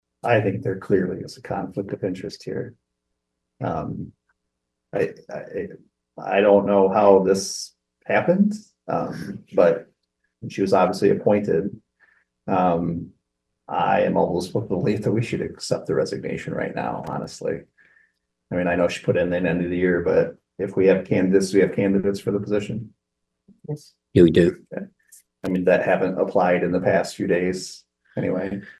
Commission Chair Tim Stoll had a blunt reaction to the situation and felt the resignation should be accepted immediately.